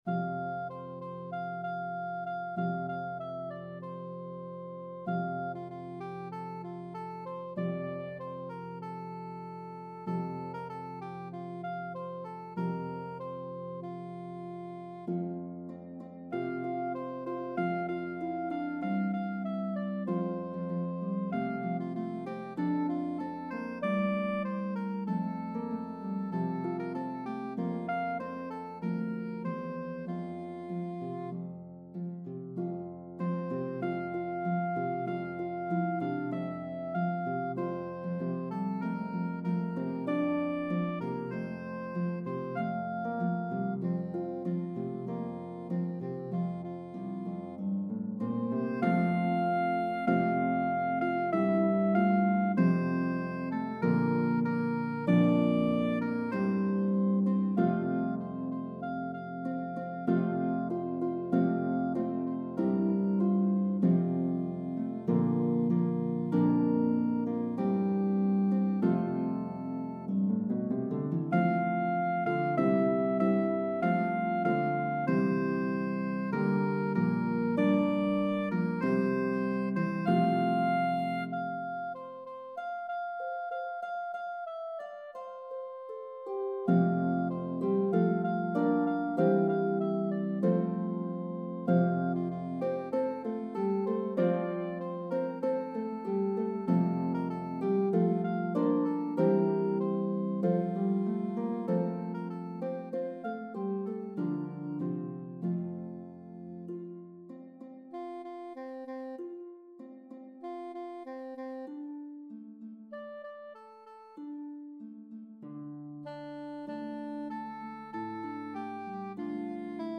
medley of traditional English & Irish Carols